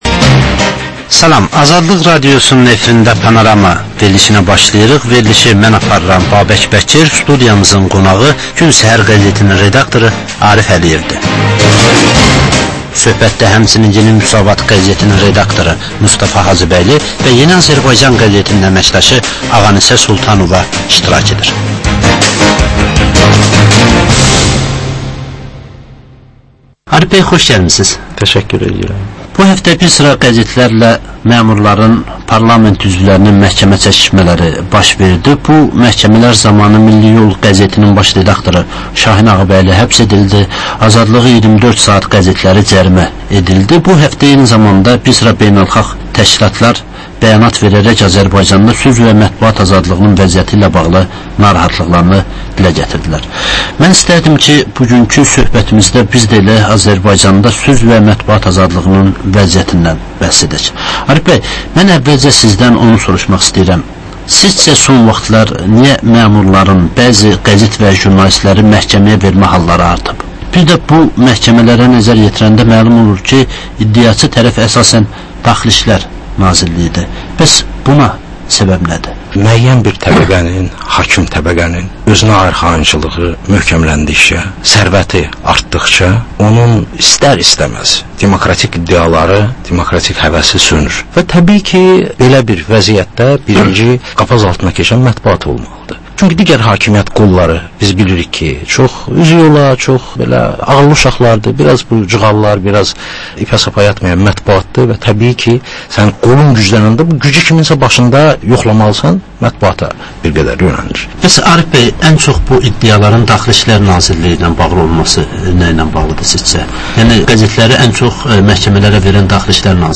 Jurnalistlər və həftənin xəbər adamıyla aktual mövzunun müzakirəsi